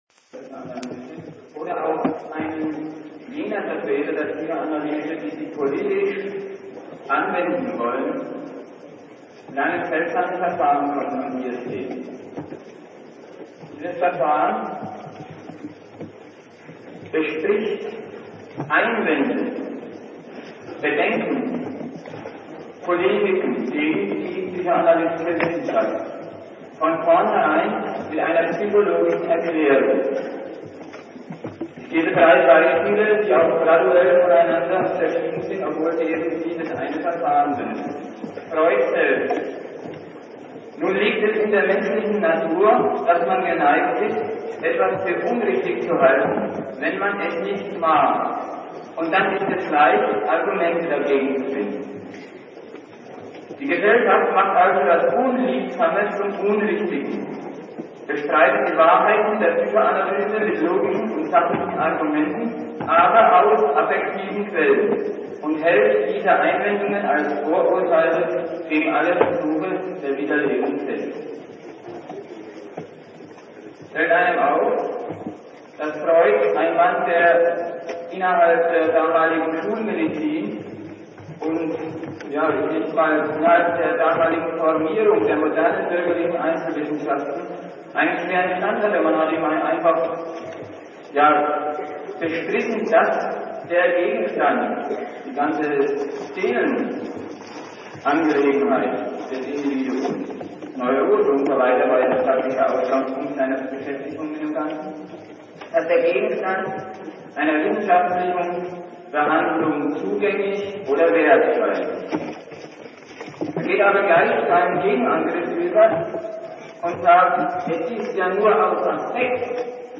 Es handelt sich um eine alte Aufnahme, die im Jahr 1978 aufgenommen wurde. Die akustische Qualität ist z.T. grenzwertig. Zum Abhören sollten Kopfhörer benutzt werden. Der Vortrag ist entsprechend der Gliederung aufgeteilt.